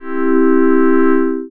Acorde de do mayor (C major chord))
Acorde de DO MAYOR
(do+mi+sol) = (C+E+G)
eqt-CMajor.wav